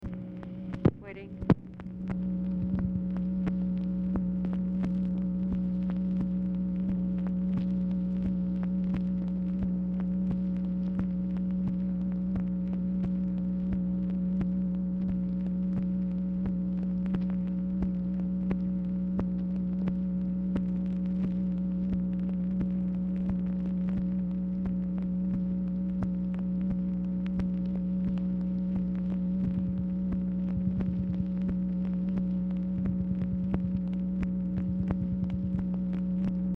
Telephone conversation # 10056, sound recording, MACHINE NOISE, 4/28/1966, time unknown | Discover LBJ
Format Dictation belt
Specific Item Type Telephone conversation